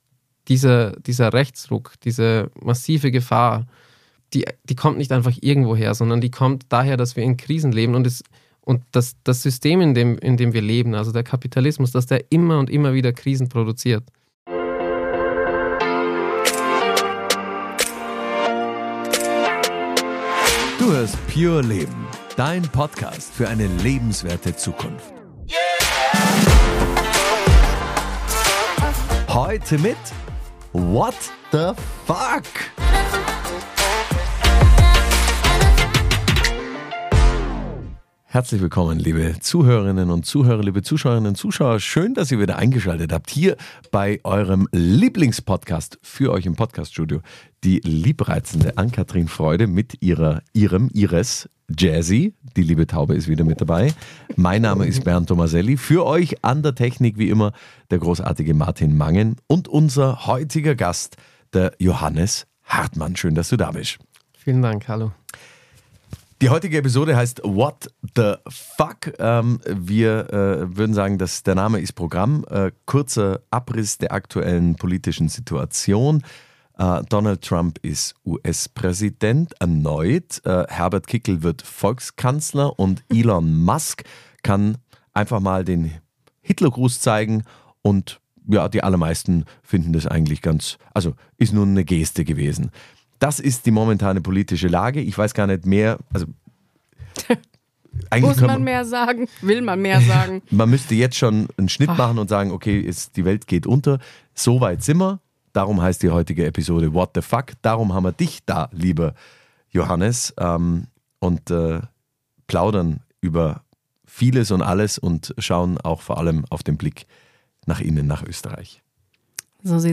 Ein aufrüttelndes Gespräch, das nicht nur die Missstände beleuchtet, sondern auch Lösungswege bietet.